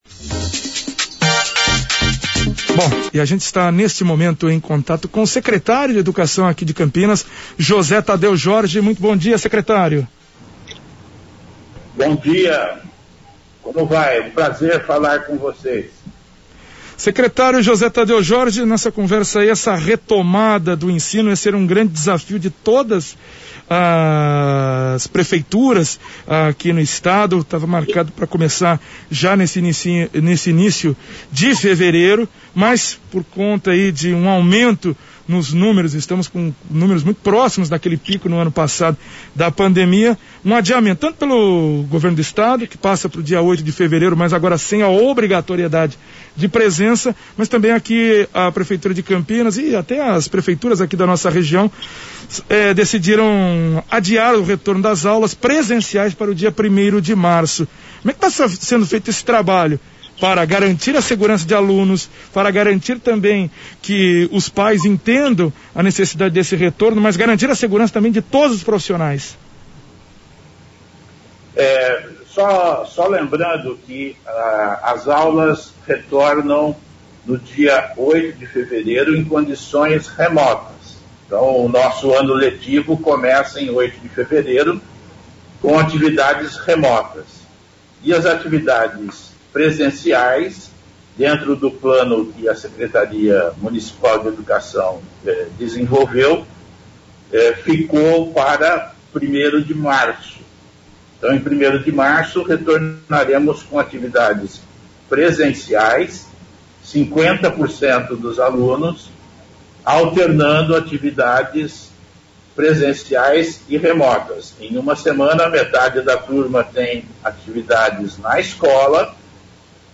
O CBN Campinas teve a participação do secretário de educação de Campinas, José Tadeu Jorge, sobre o cronograma de volta às aulas em Campinas. A Secretaria de Educação de Campinas (SP) decidiu adiar o retorno das aulas presenciais para 1º de março. O início do ano letivo está mantido para 8 de fevereiro, mas com aulas remotas.